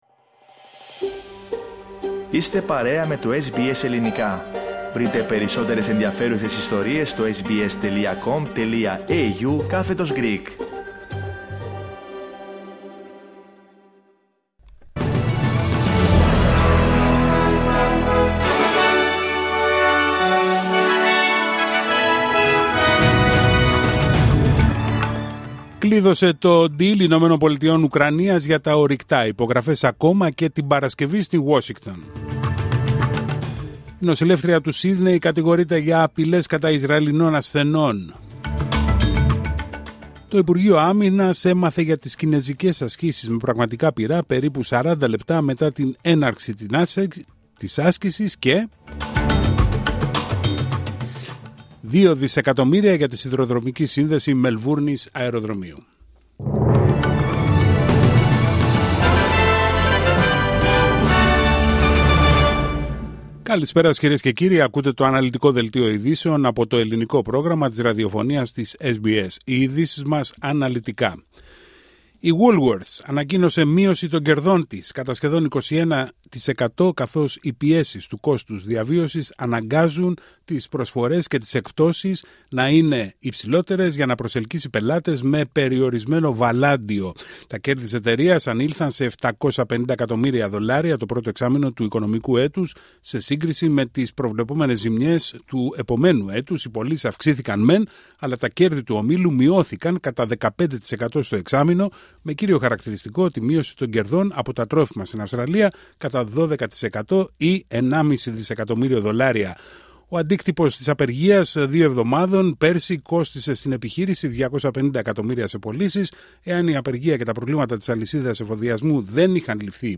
Δελτίο ειδήσεων Τετάρτη 26 Φεβρουαρίου 2025